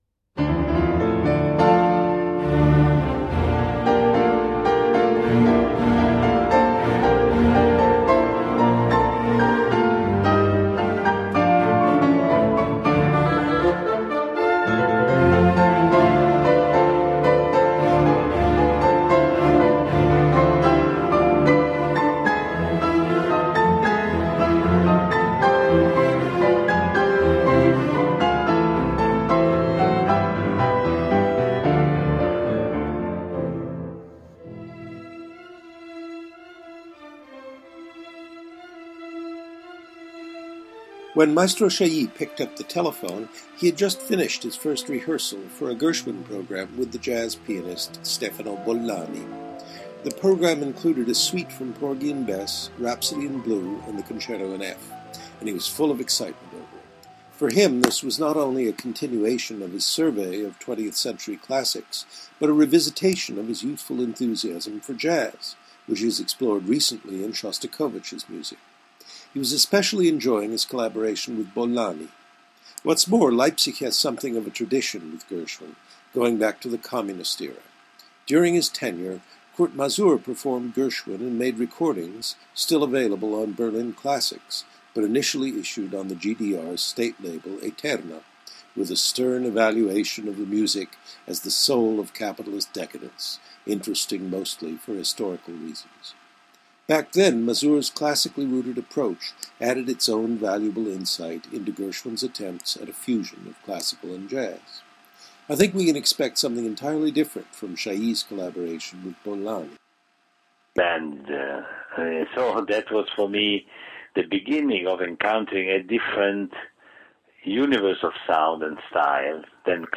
As he says in the interview, before he took up his position at the Gewandhaus, he had a sabbatical year to study its history, which is uniquely significant in the history of symphonic music.